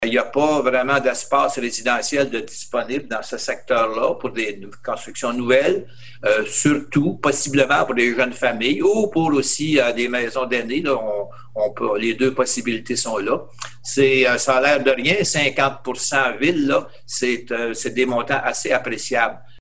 Lors de la présentation du budget 2021, le maire Jean-Guy Dubois a donné plus de détails sur ce programme de péréquation exclusif au secteur Sainte-Gertrude.